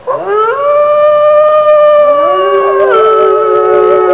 wolfpack.wav